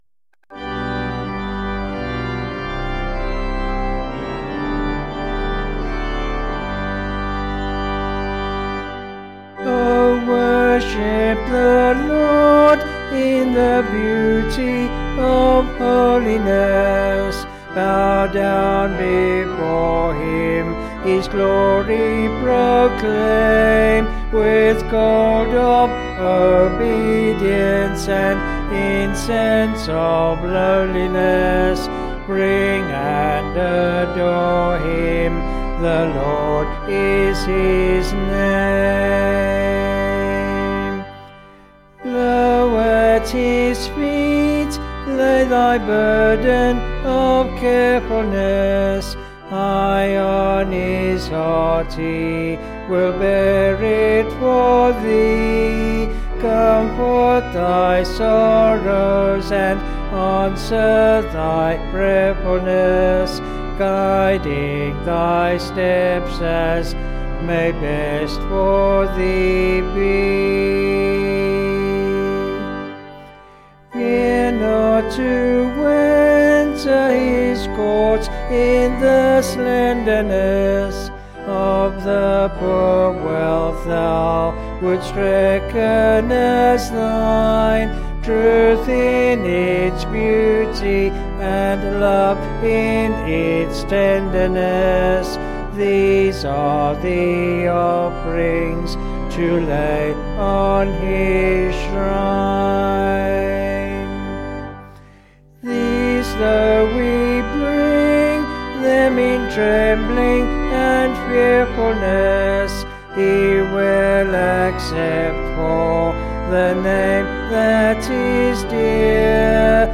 Vocals and Organ   264.6kb Sung Lyrics